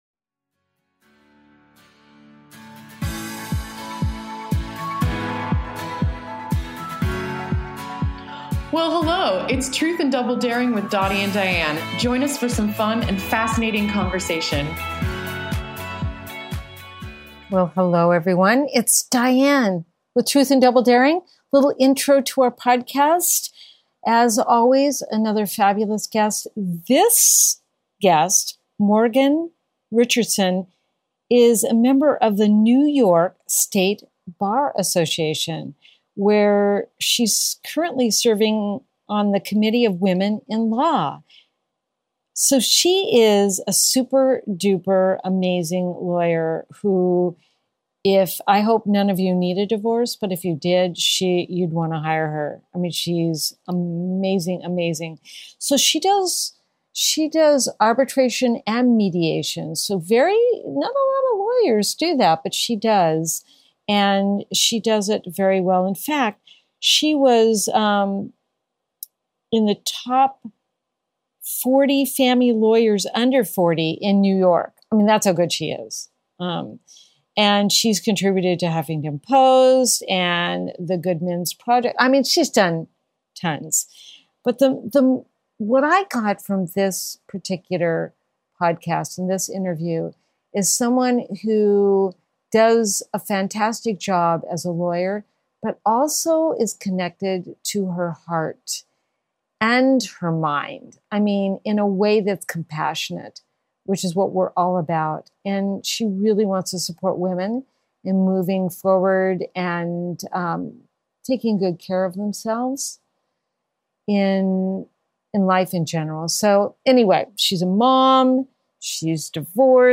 Podcast Interview: Truth and Double Daring